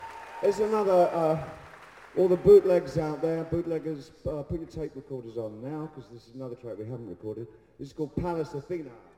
I just came across this when preparing the Prague 97 concert for upload:
Bowie also mentioned bootleggers in some other concerts in 97 in about the same way.